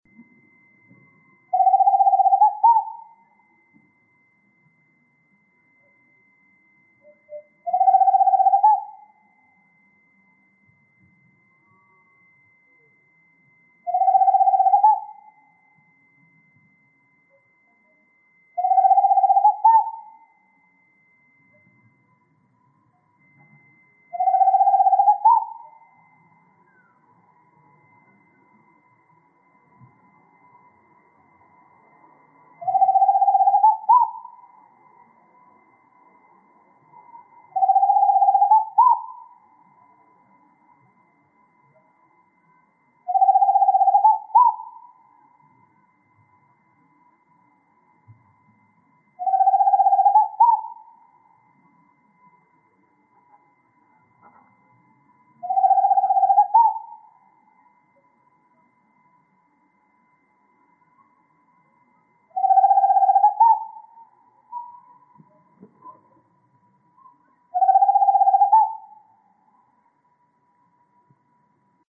Tropical Screech Owl (Megascops choliba)
Life Stage: Adult
Province / Department: Entre Ríos
Location or protected area: Villa Paranacito
Condition: Wild
Certainty: Recorded vocal